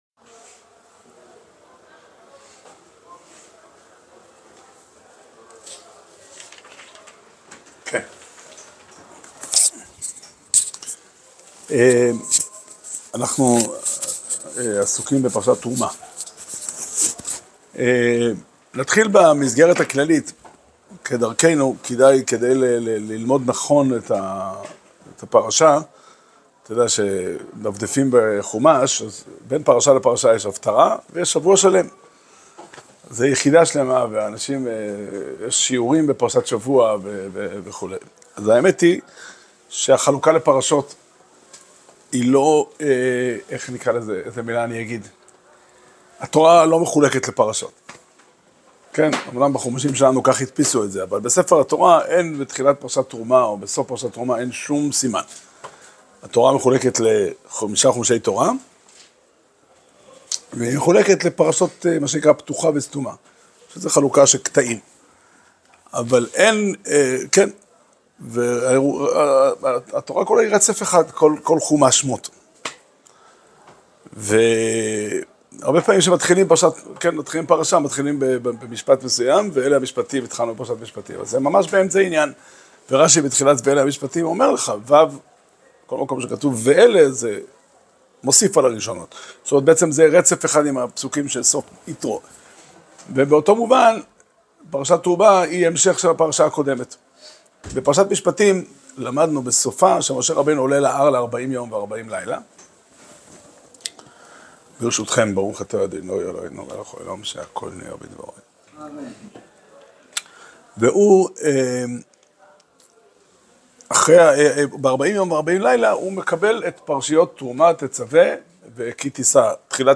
שיעור שנמסר בבית המדרש פתחי עולם בתאריך כ"ו שבט תשפ"ה